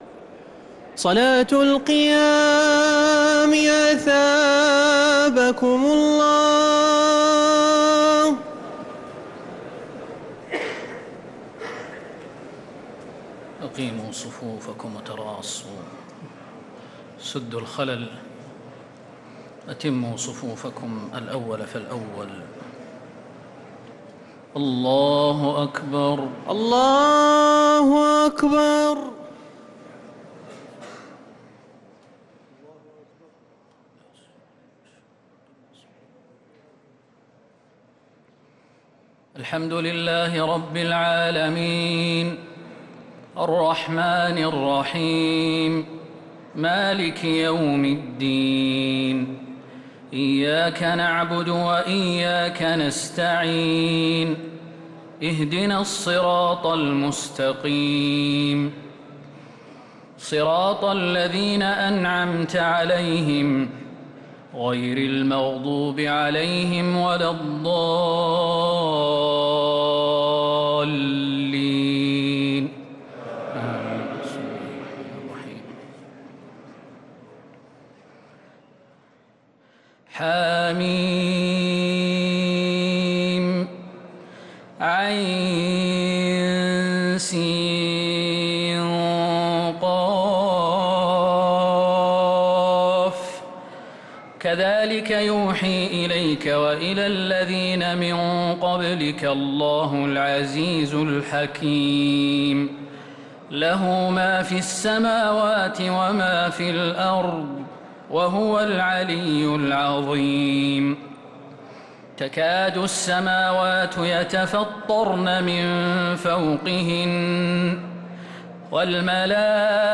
تراويح ليلة 26 رمضان 1446هـ سورة الشورى كاملة و الزخرف (1-45) | taraweeh 26th night Ramadan 1446H Surah Ash-Shuraa and Az-Zukhruf > تراويح الحرم النبوي عام 1446 🕌 > التراويح - تلاوات الحرمين